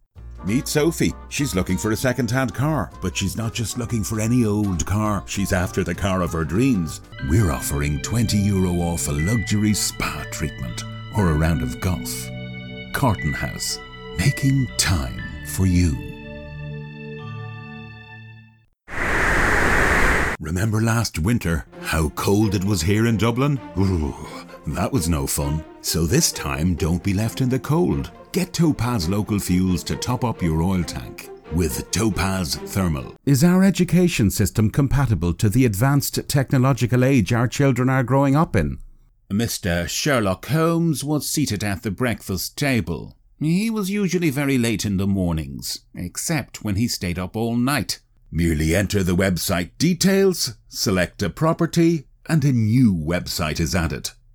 Gender: Male
• Warm & Friendly
• Narrations
• Deep Voice
• Commercials
• British Accents
• Announcer Voice